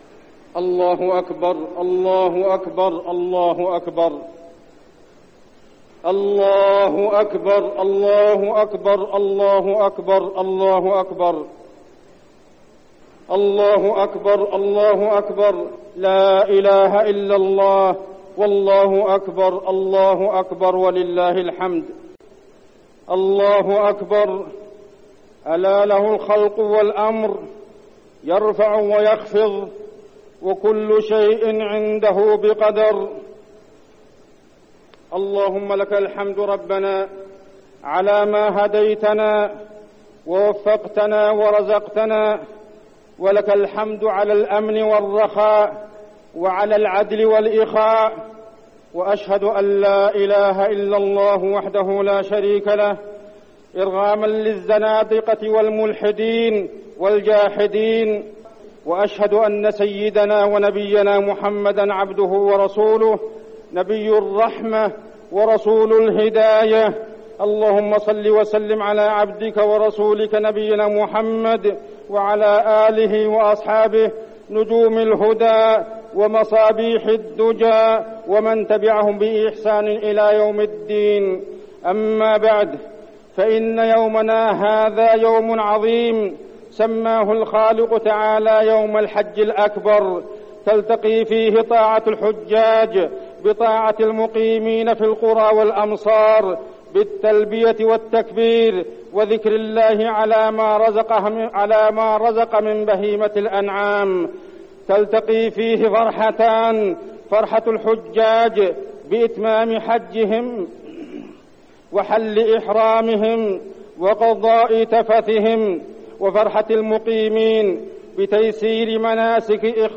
خطبة عيد الأضحى - المدينة - الشيخ عبدالله الزاحم
تاريخ النشر ١٠ ذو الحجة ١٤١١ هـ المكان: المسجد النبوي الشيخ: عبدالله بن محمد الزاحم عبدالله بن محمد الزاحم خطبة عيد الأضحى - المدينة - الشيخ عبدالله الزاحم